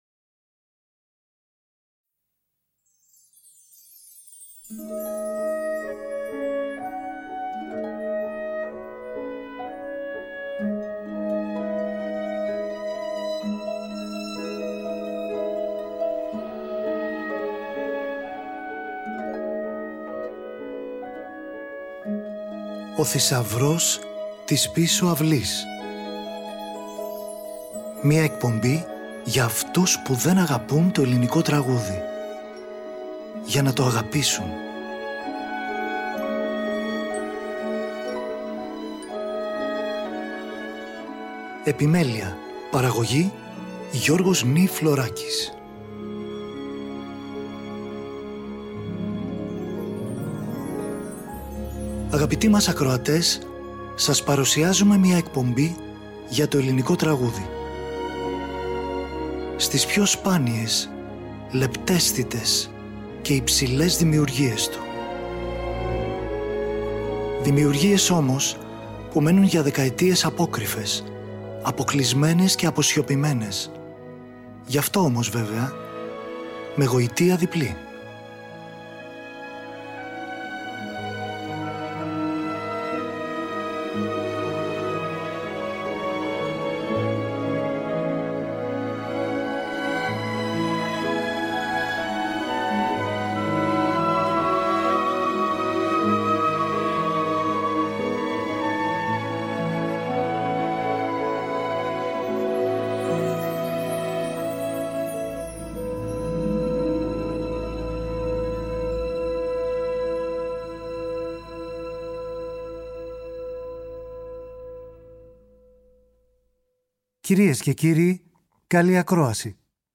έργα για φωνή και πιάνο